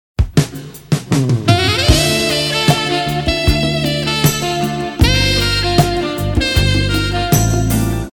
uma música romântica.